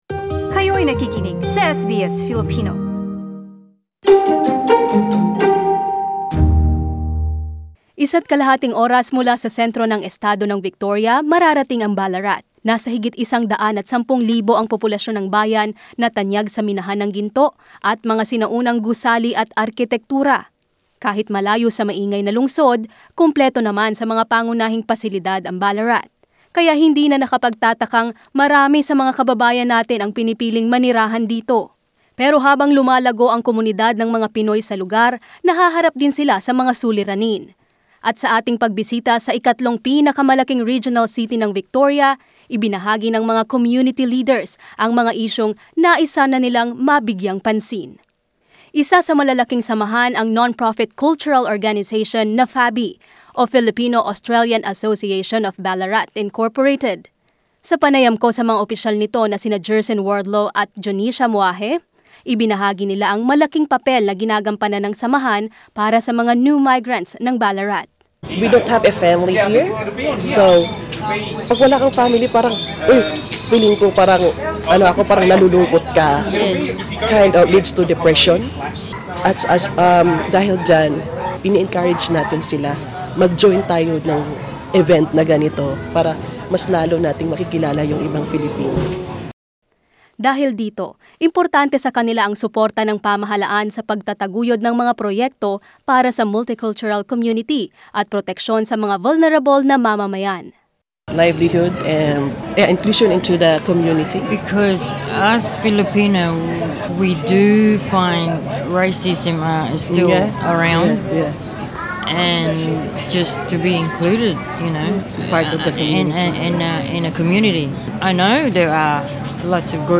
Members of Filipino community in Ballarat shared their views Source: SBS